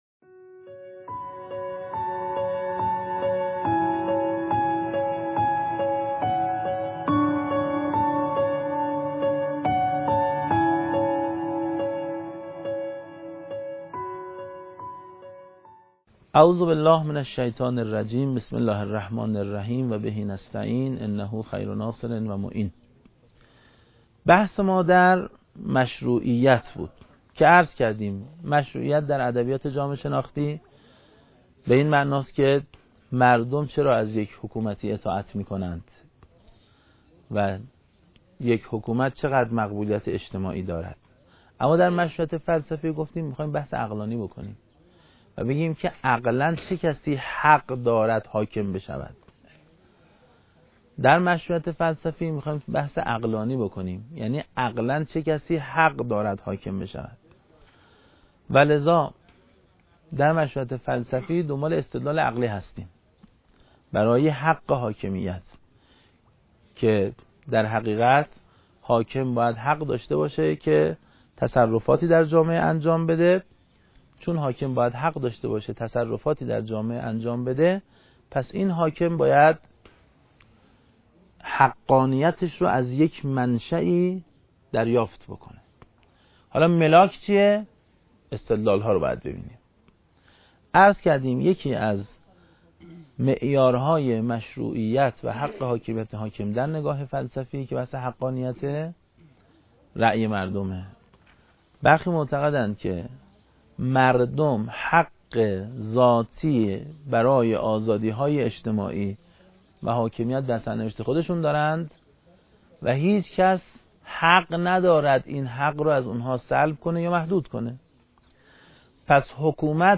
رادیو فکرت M6-J05.mp3 15.69M 🎙 بشنوید | صوت فلسفه سیاست 📖 درس پنجم